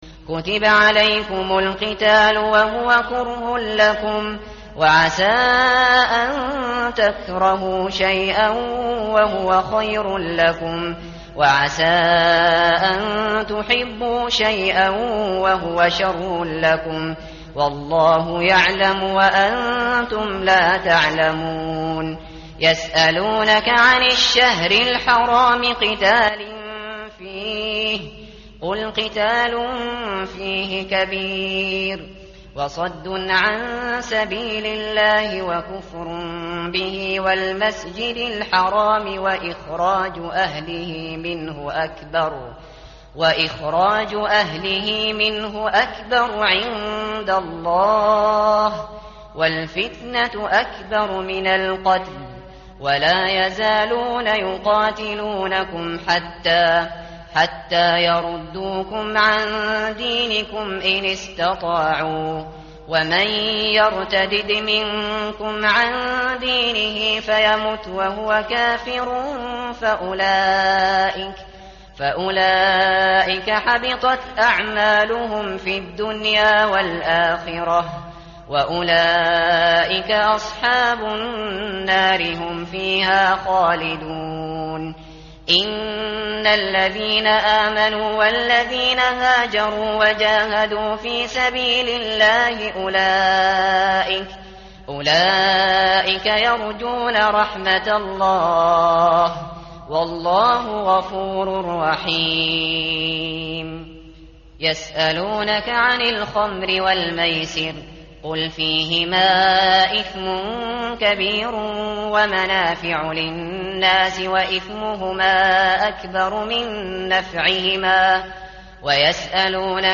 متن قرآن همراه باتلاوت قرآن و ترجمه
tartil_shateri_page_034.mp3